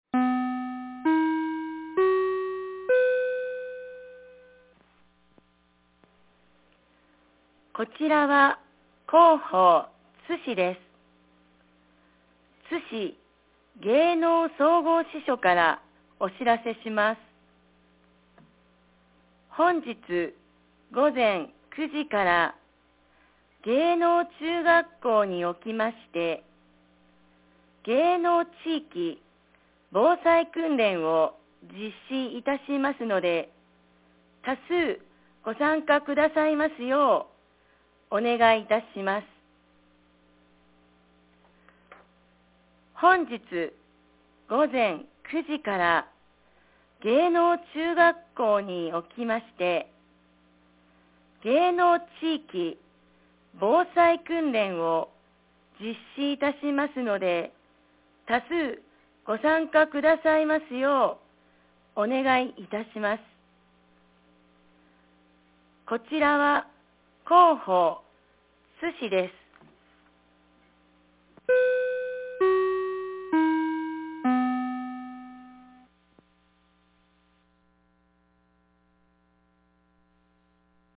2024年11月30日 07時09分に、津市より椋本、明、安西、雲林院、河内へ放送がありました。